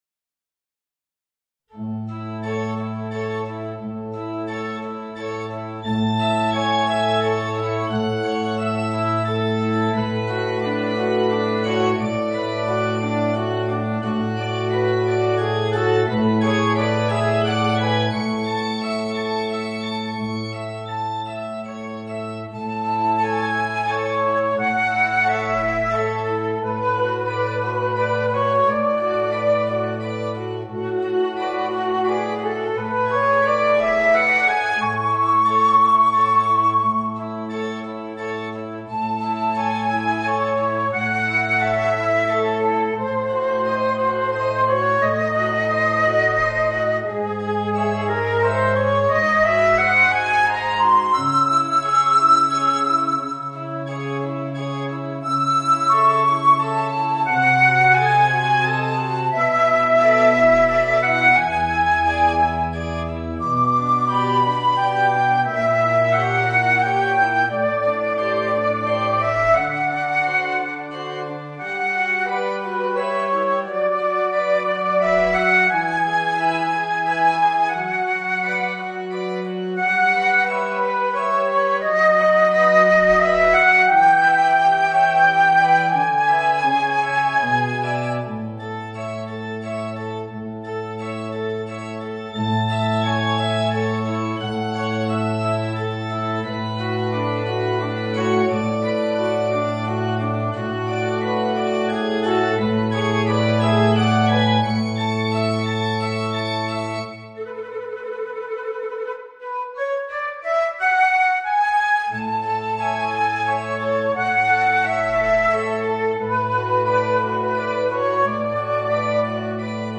Voicing: Flute and Organ